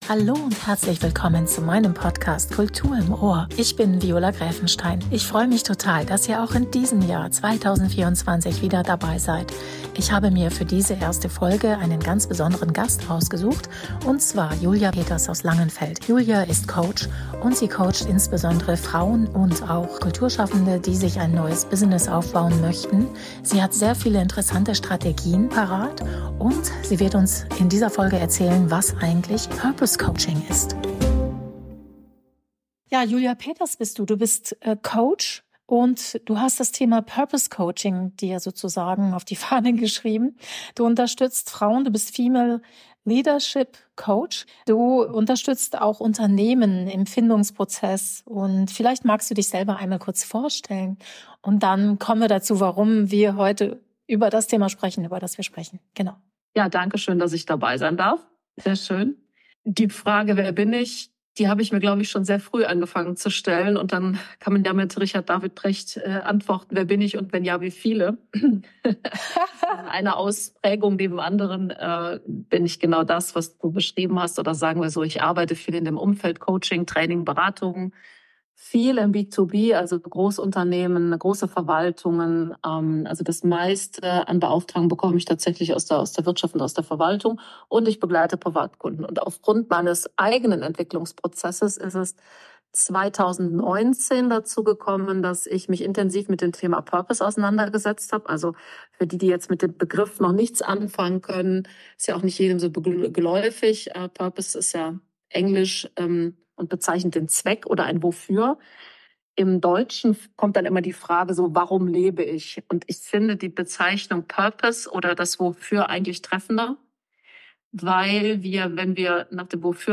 Dazu kommen Menschen aus Kunst und Kultur zu Wort, die ganz eigene Wege gehen und etwas zum Thema erfolgreiche Selbstvermarktung und Kreativität beitragen können.